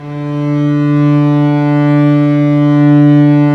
Index of /90_sSampleCDs/Roland LCDP13 String Sections/STR_Vcs FX/STR_Vcs Sordino